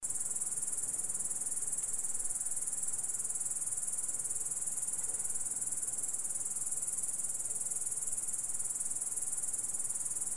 cicadas